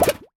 Universal UI SFX / Clicks
UIClick_Bubble Pop Metallic 03.wav